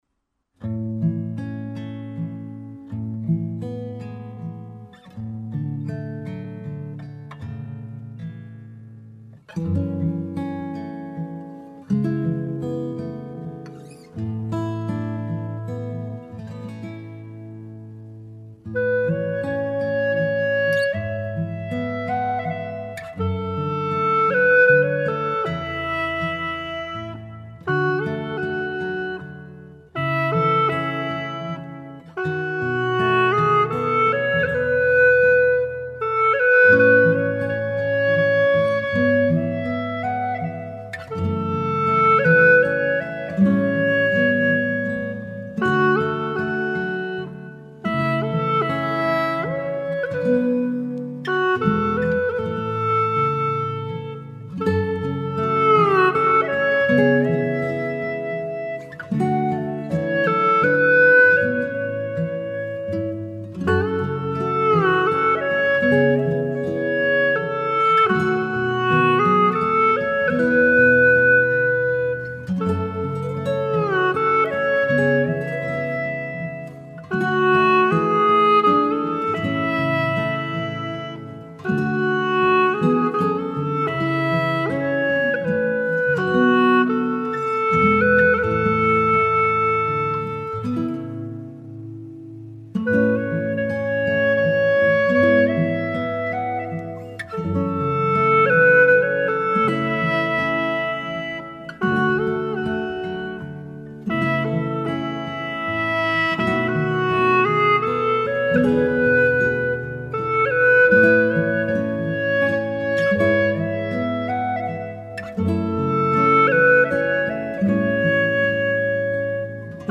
调式 : A